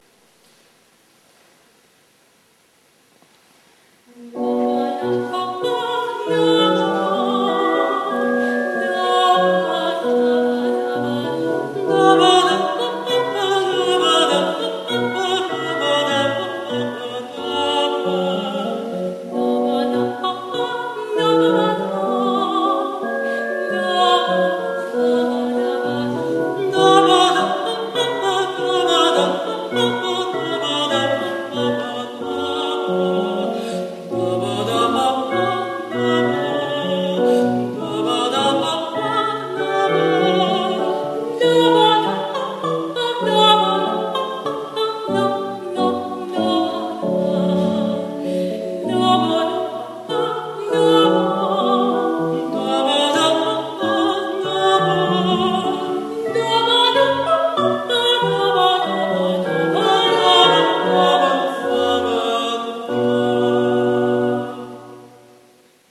MP3 Menuet suite n bwv1067 en bminor